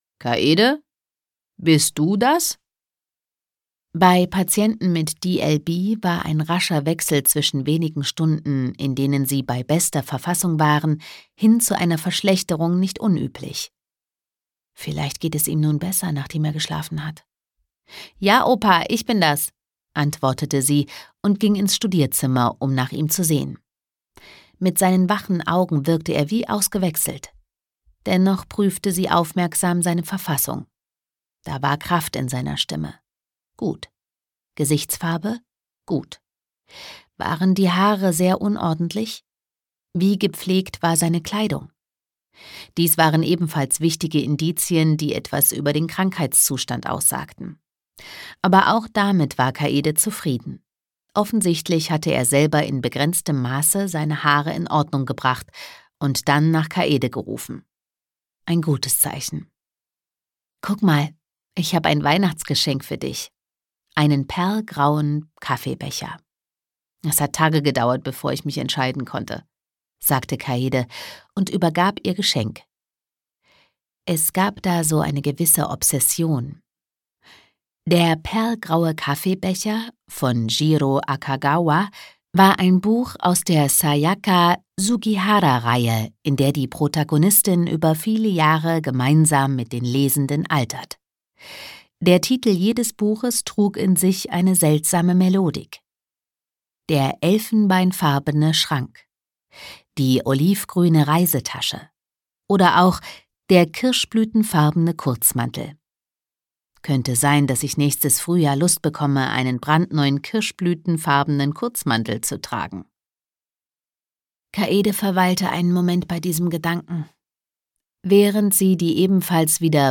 Die Rätsel meines Großvaters - Masateru Konishi | argon hörbuch
Gekürzt Autorisierte, d.h. von Autor:innen und / oder Verlagen freigegebene, bearbeitete Fassung.